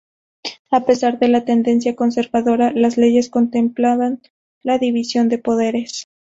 con‧ser‧va‧do‧ra
/konseɾbaˈdoɾa/